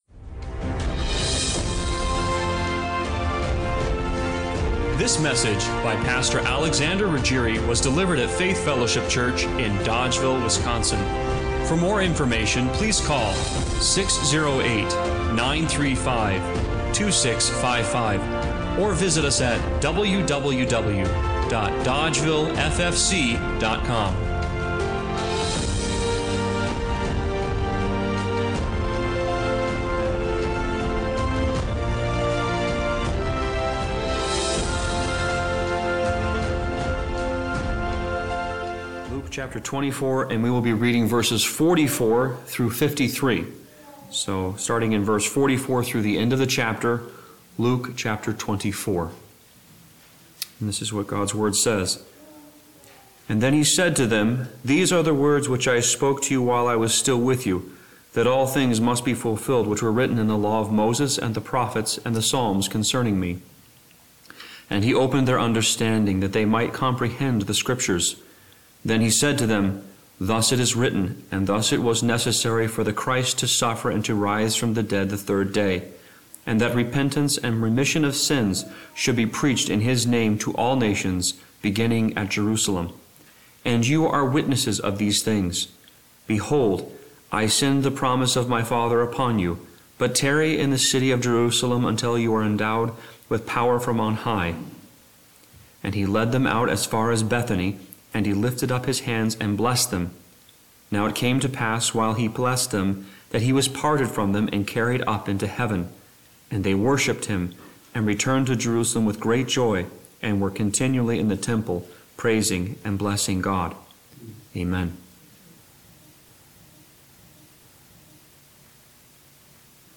Acts 2:1 Service Type: Sunday Morning Worship What if the power we’re waiting for is waiting on us?